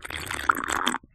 drink.ogg